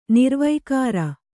♪ nirvaikāra